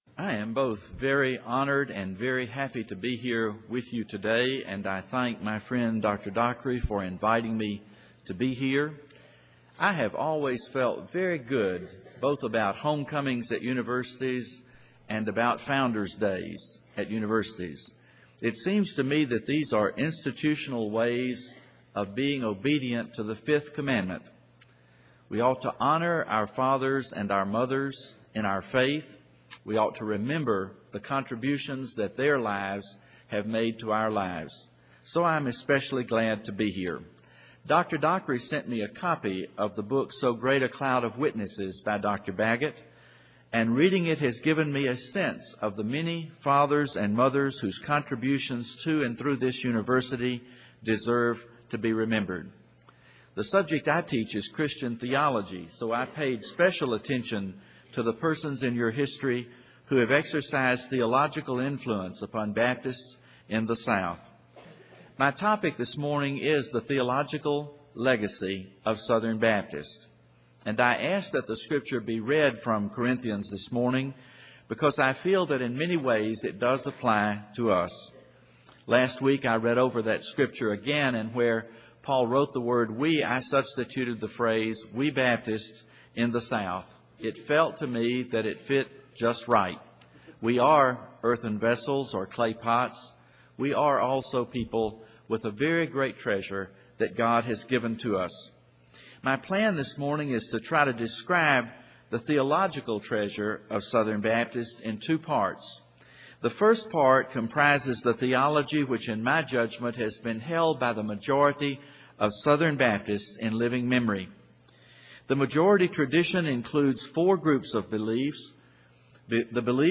Chapel Service: Homecoming & Founder's Day